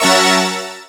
Synth Lick 50-08.wav